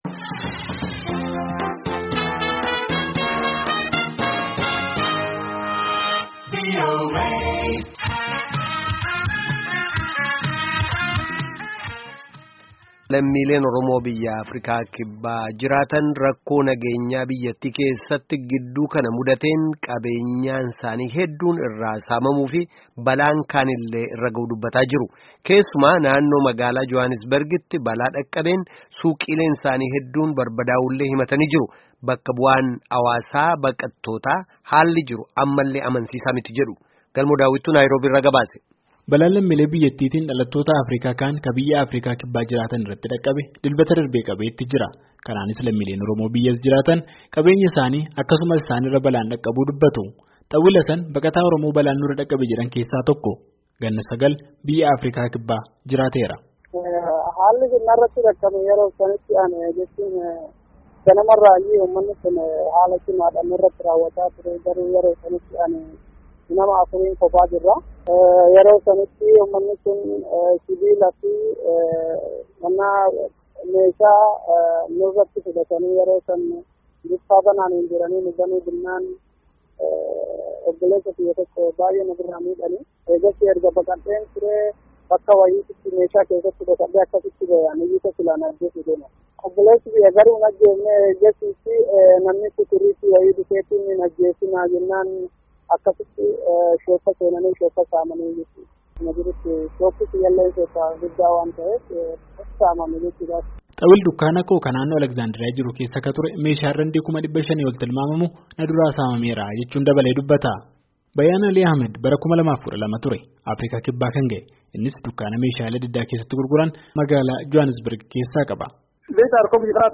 Gabaasa dhimma saamicha Afriikaa Kibbaa caqasaa